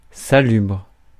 Ääntäminen
Ääntäminen France: IPA: [sa.lybʁ] Haettu sana löytyi näillä lähdekielillä: ranska Käännös Ääninäyte Adjektiivit 1. healthy US 2. healthful 3. salubrious US Suku: f .